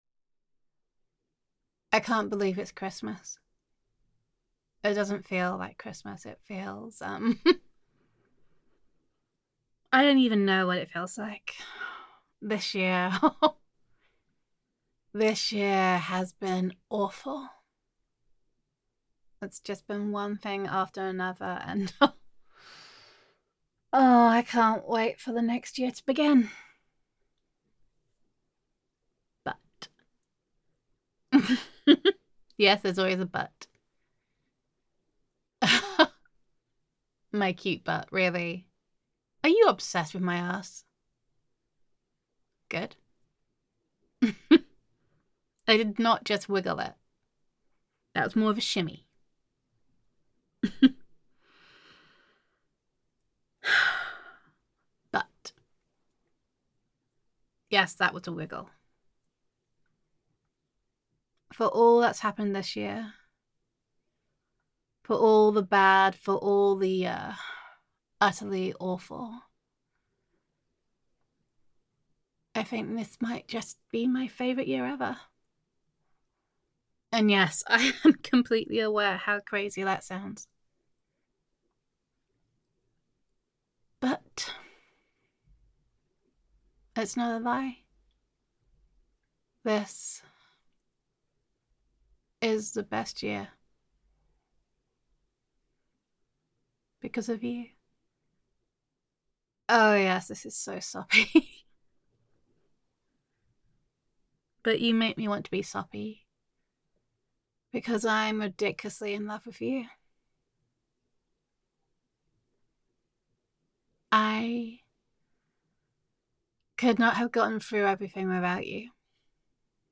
[F4A] The Best Year [Girlfriend Roleplay][You Make Everything Better][Adoration][2020 Sucks][Appreciation][Friends to Lovers][Gender Neutral][Your Loving Girlfriend Appreciates You]